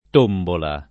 tombola [ t 1 mbola ]